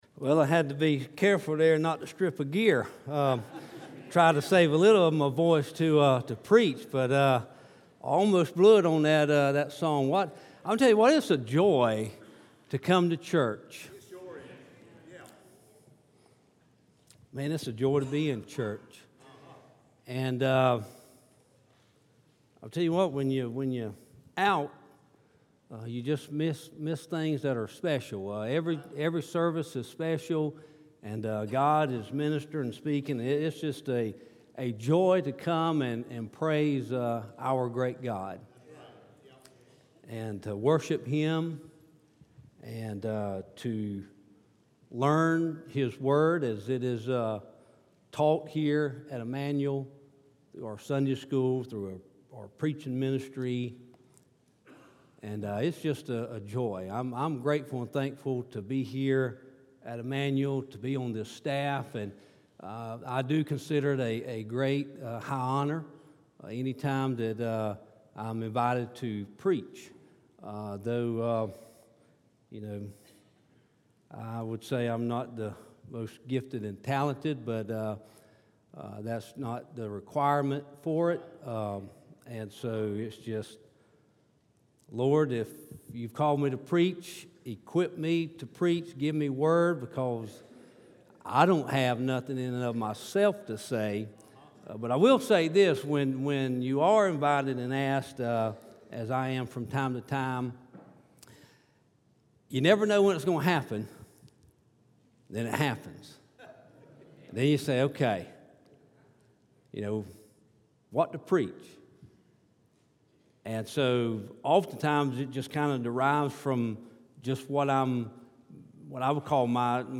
From the evening worship service on Sunday, January 13, 2019